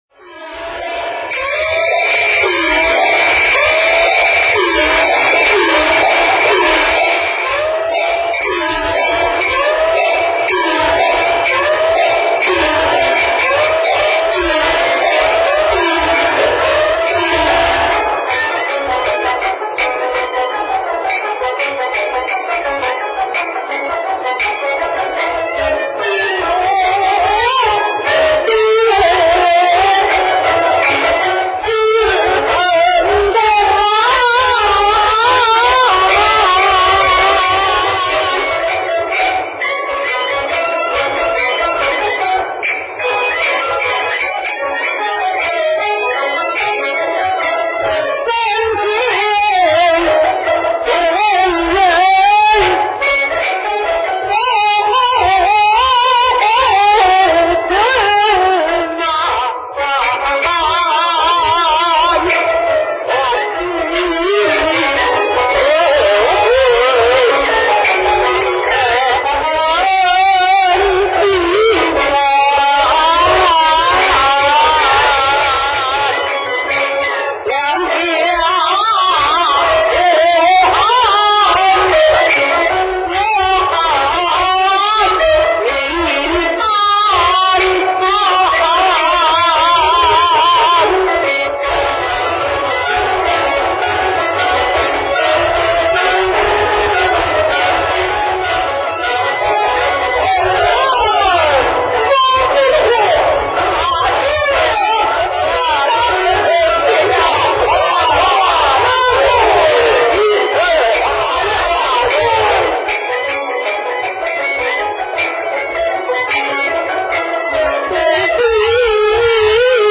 京剧
实况